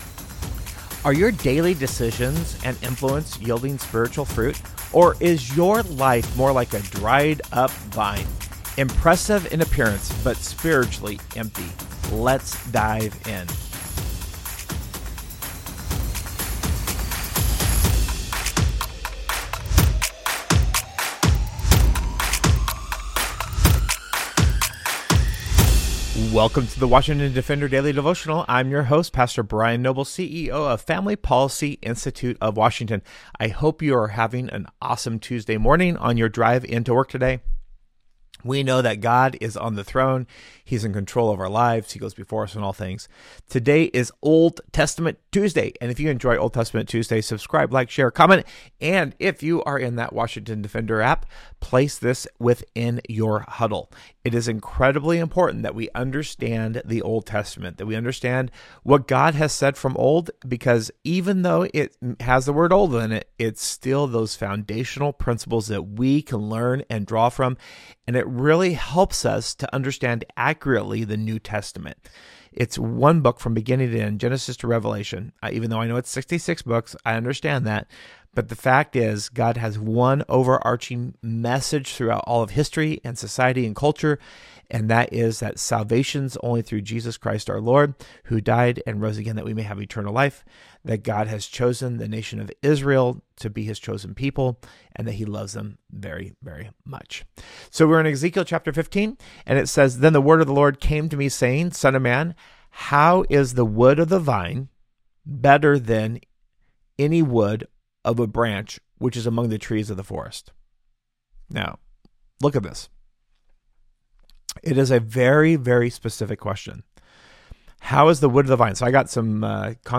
A Devotion for your drive into work: